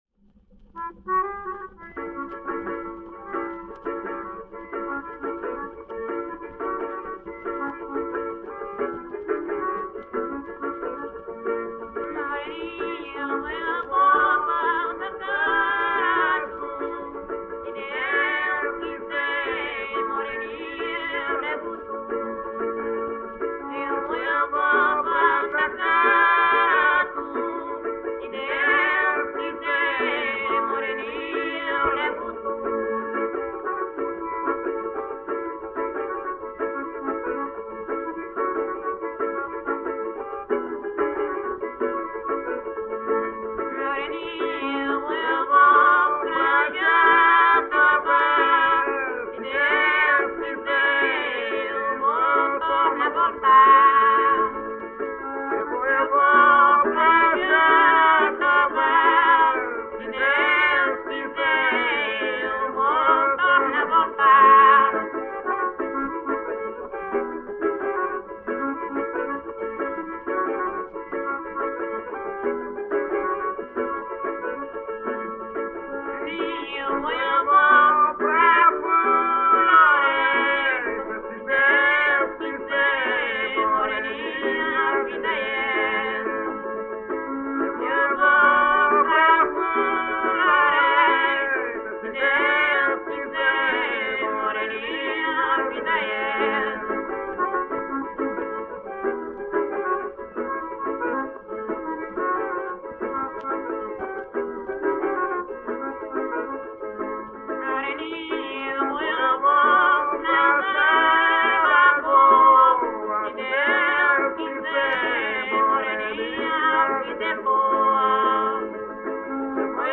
Samba de Pernambuco - ""Moreninha""